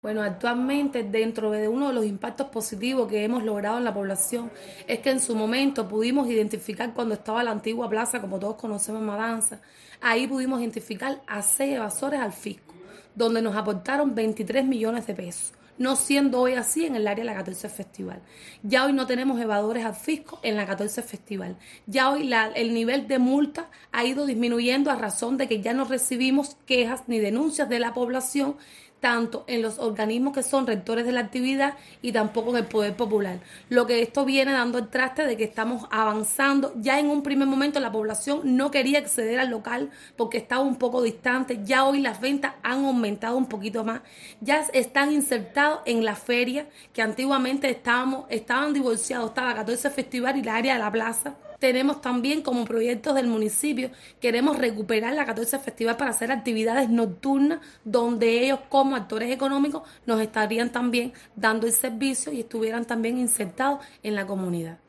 La funcionaria del gobierno municipal también reconoció varios de los impactos positivos que ya genera la medida, sobre todo relacionados con la organización y el cumplimiento de las regulaciones económicas.